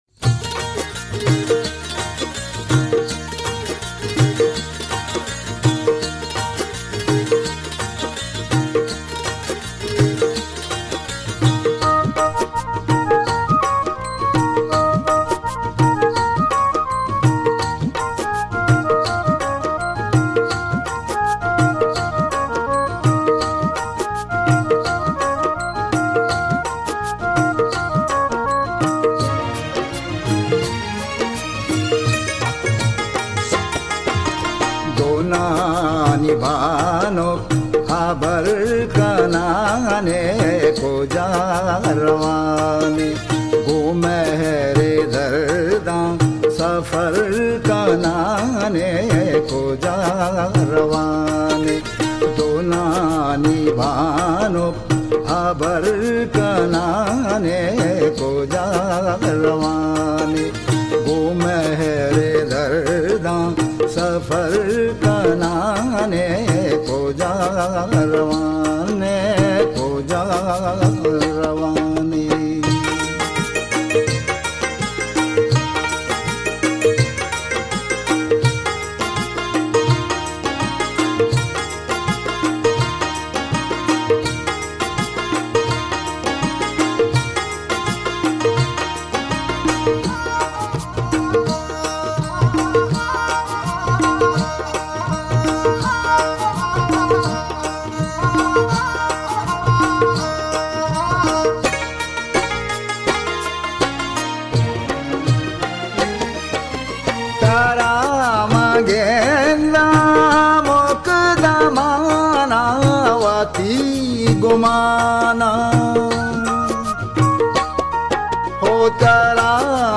موسیقی بلوچستان
آهنگ بلوچی